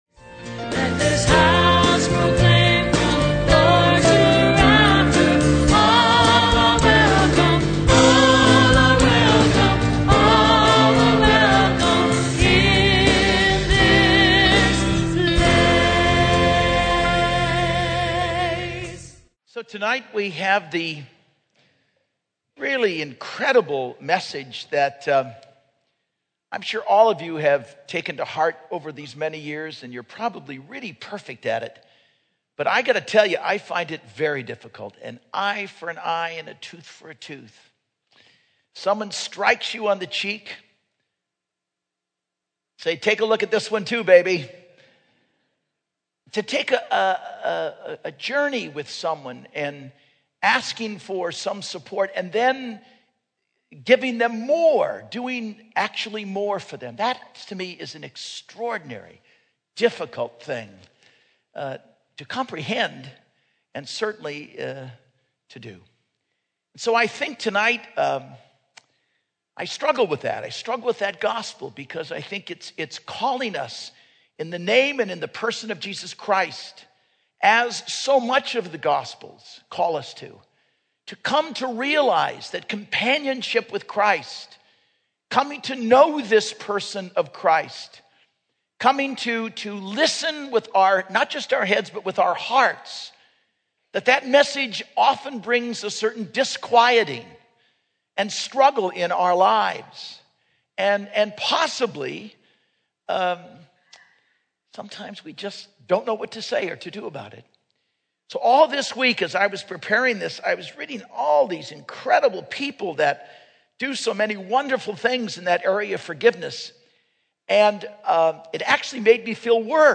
Homily - 2/20/11 - 7th Sunday Ordinary Time